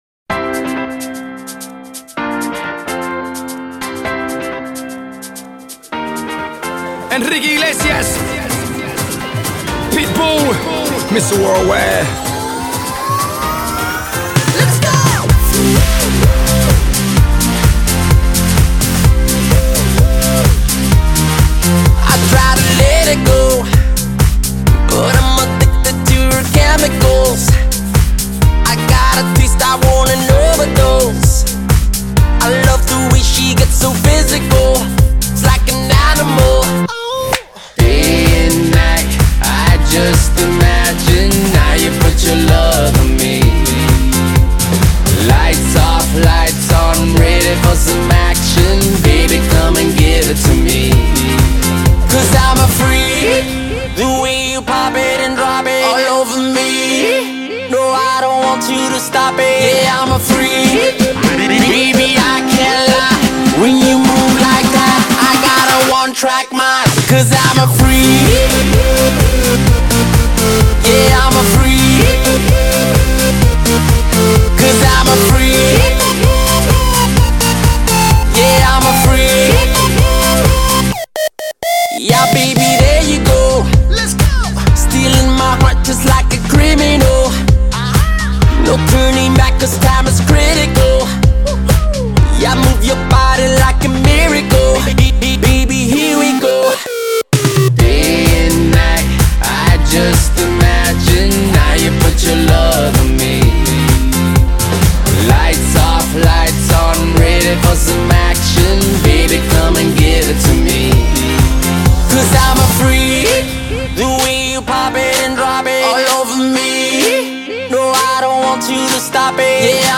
Poslušajte kako zvuči najnoviji dance duet.